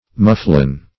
muflon - definition of muflon - synonyms, pronunciation, spelling from Free Dictionary Search Result for " muflon" : The Collaborative International Dictionary of English v.0.48: Muflon \Muf"lon\, n. (Zool.)
muflon.mp3